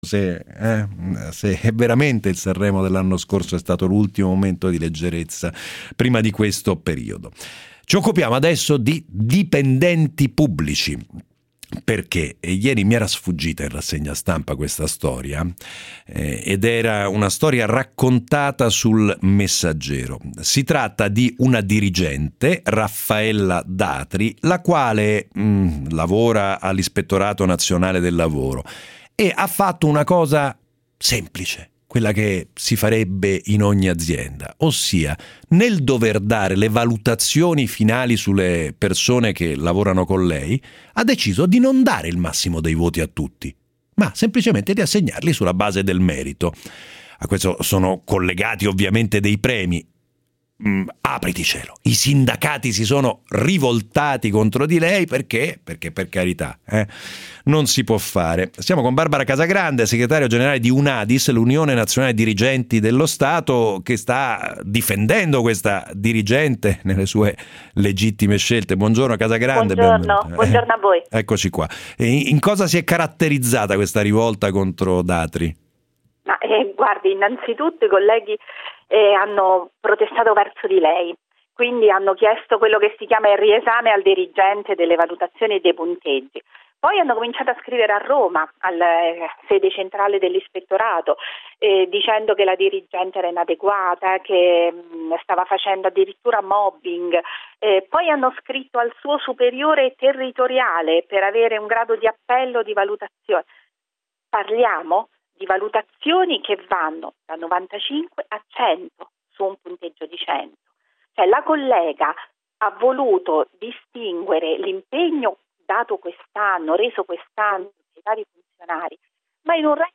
In una breve intervista, tutte quante le ragioni del declino della nostra nazione a cui dobbiamo sbrigarci di porre rimedio se non vogliamo sparire dalla cartina del vecchio continente.